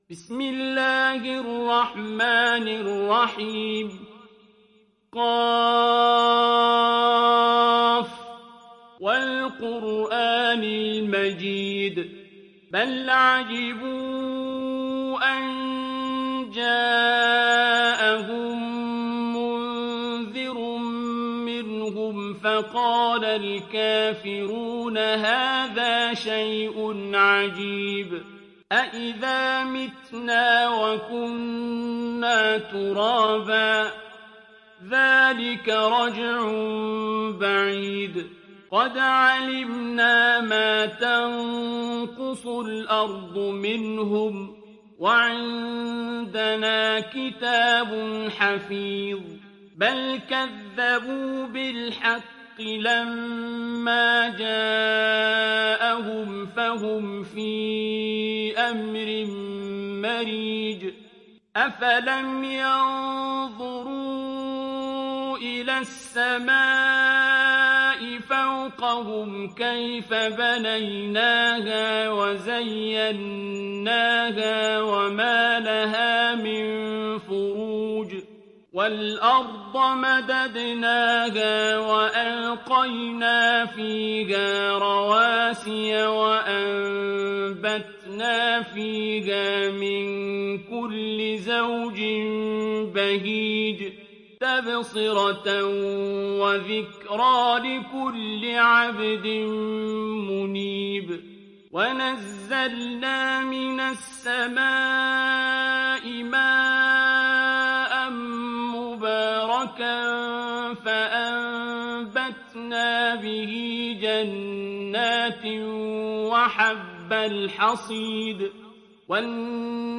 تحميل سورة ق mp3 بصوت عبد الباسط عبد الصمد برواية حفص عن عاصم, تحميل استماع القرآن الكريم على الجوال mp3 كاملا بروابط مباشرة وسريعة